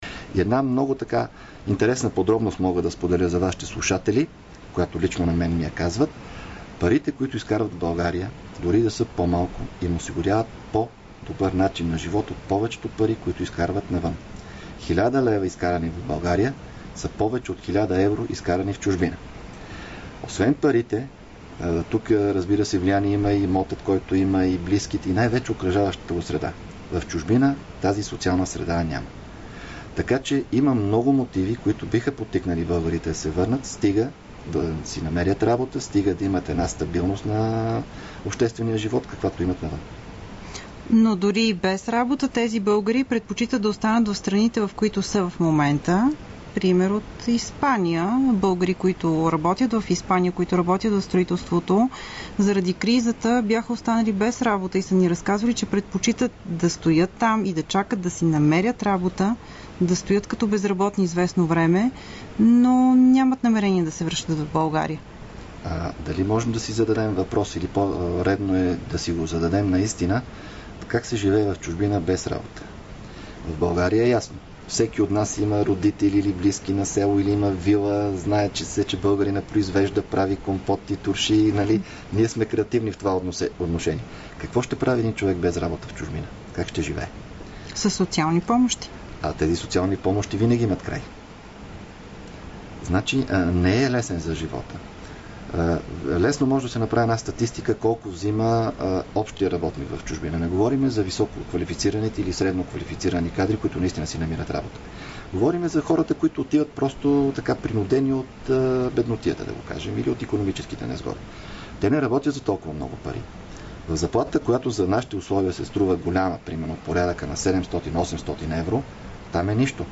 Записи от интервюто за БНР на Росен Иванов – шеф на Държавната агенция за българите в чужбина (ДАБЧ)